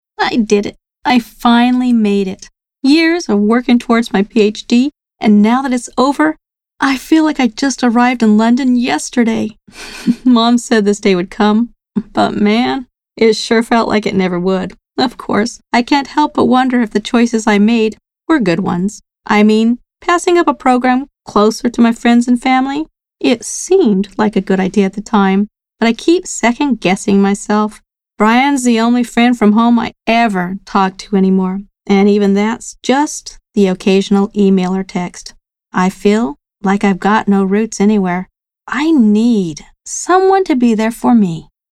My narration style is thoughtful and immersive, often described as grounding and familiar, helping listeners feel at home within the world of a story.
Character Demo
memoir-narrative.mp3